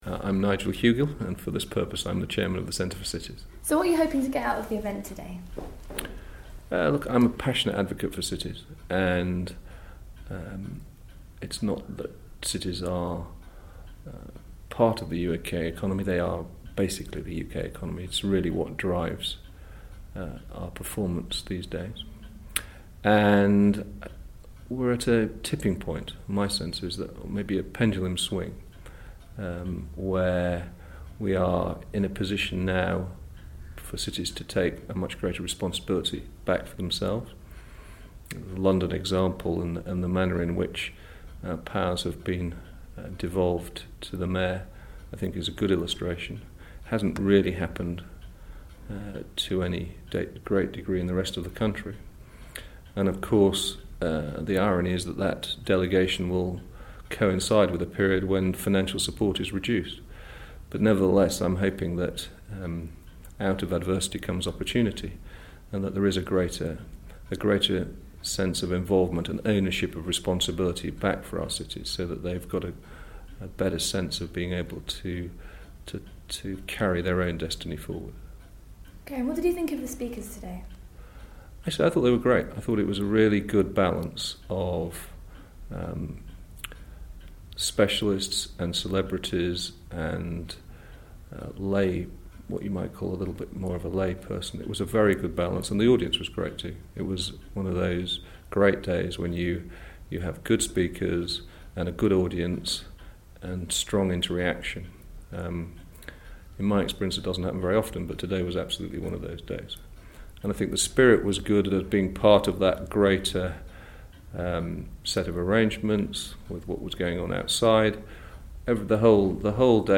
IBM Start Day 1 - Interview